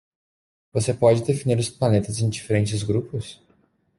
Read more to define Frequency C1 Pronounced as (IPA) /de.fiˈni(ʁ)/ Etymology Borrowed from Latin dēfīniō In summary Borrowed from Latin dēfīnīre (“to bound, to limit”).